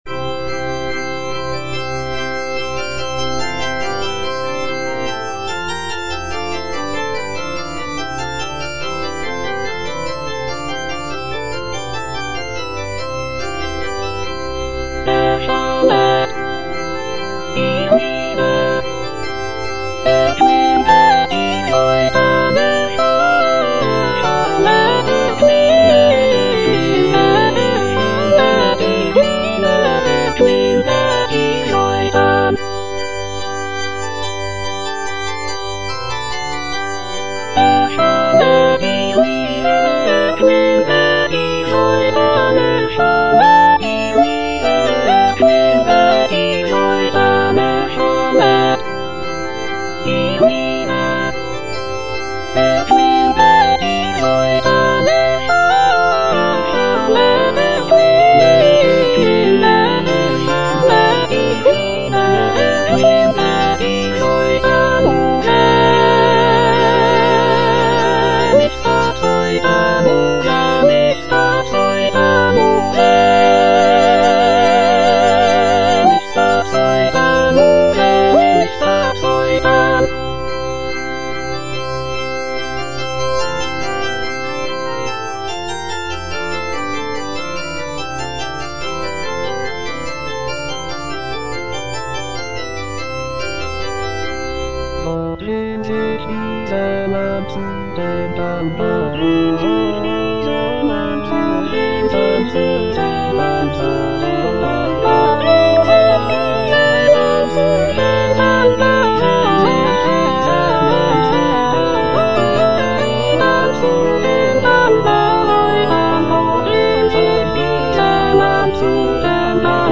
Cantata
Soprano (Emphasised voice and other voices) Ads stop